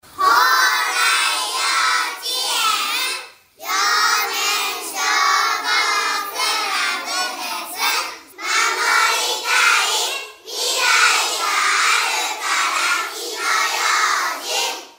「火災予防運動」や「歳末特別警戒」の一環で、管内の子ども達（幼年消防クラブ員）が録音した音声を消防車から流し、パトロールをします。
子ども達は、かわいい元気いっぱいの声で「守りたい 未来があるから 火の用心」と音声を録音して協力して頂きました。